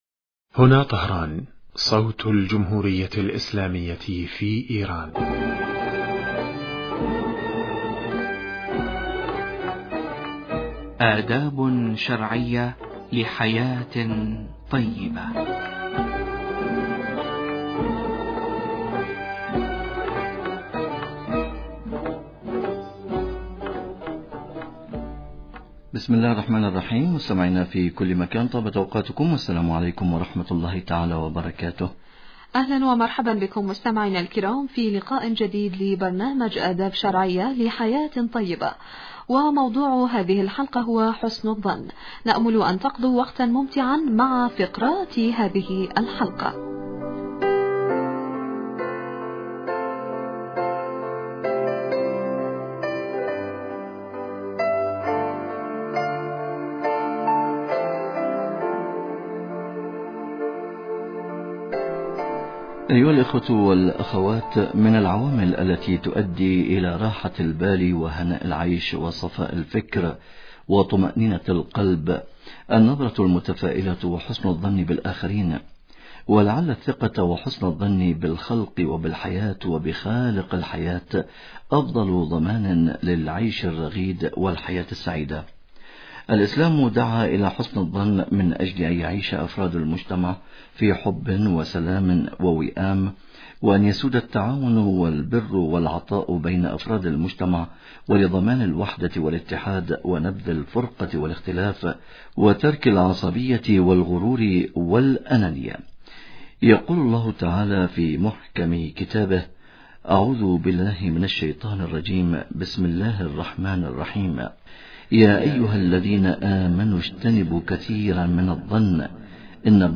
مستمعينا في كل مكان طابت أوقاتكم والسلام عليكم ورحمة الله وبركاته. أهلا ومرحبا بكم مستمعينا الكرام في لقاءٍ جديد لبرنامج(آداب شرعية لحياة طيبة) وموضوع هذه الحلقة هو(حسن الظن).